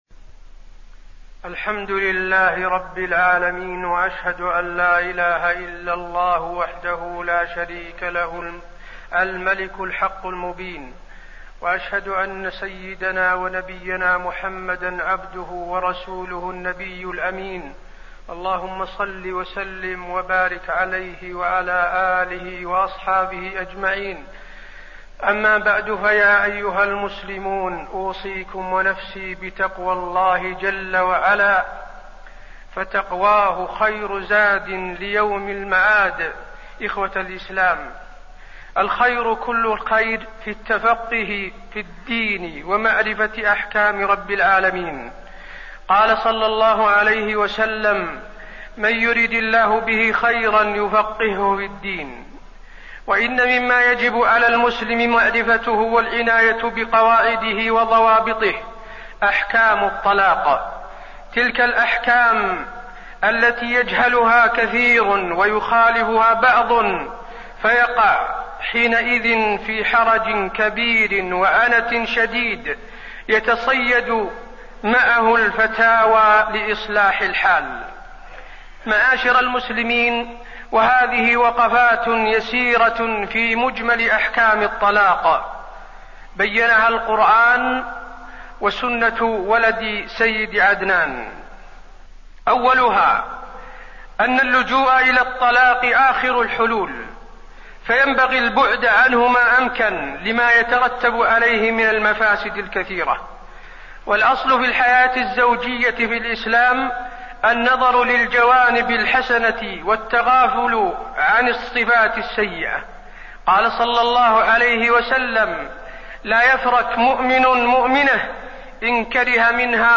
تاريخ النشر ٢٨ جمادى الآخرة ١٤٣١ هـ المكان: المسجد النبوي الشيخ: فضيلة الشيخ د. حسين بن عبدالعزيز آل الشيخ فضيلة الشيخ د. حسين بن عبدالعزيز آل الشيخ وقفات عن الطلاق